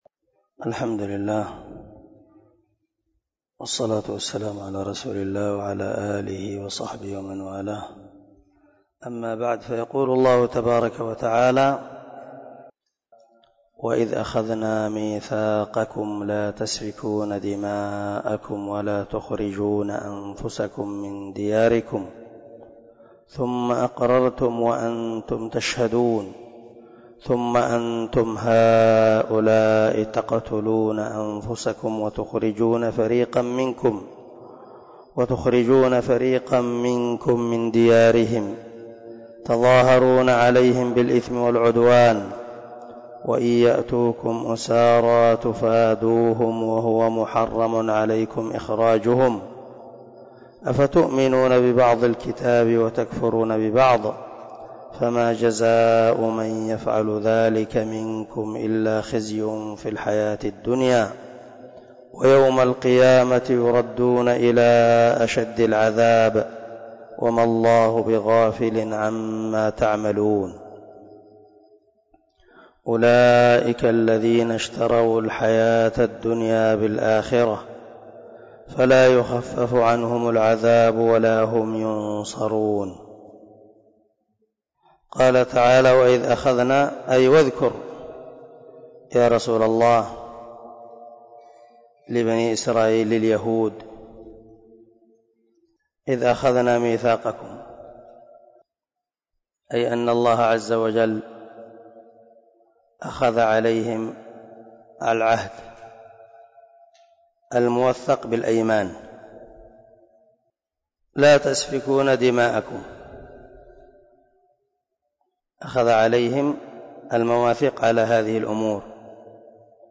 037الدرس 27 تفسير آية ( 84 – 86 ) من سورة البقرة من تفسير القران الكريم مع قراءة لتفسير السعدي
دار الحديث- المَحاوِلة- الصبيحة.